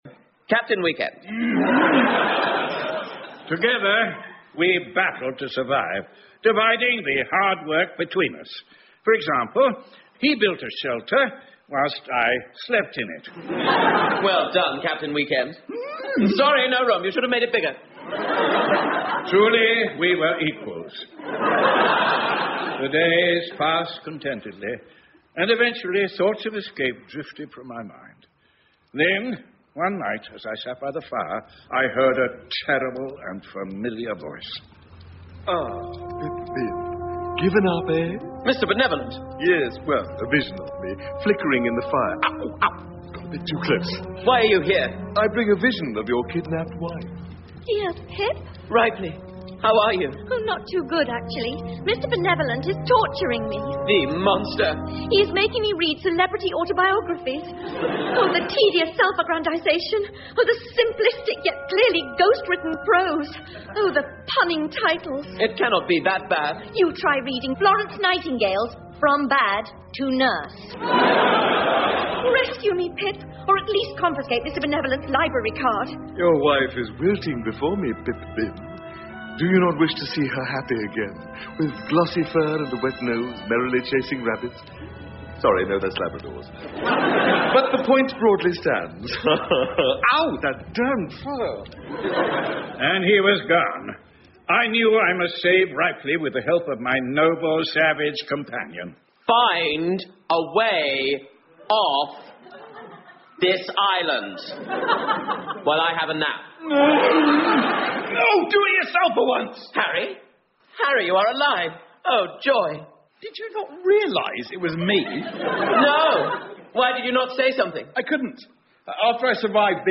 英文广播剧在线听 Bleak Expectations 109 听力文件下载—在线英语听力室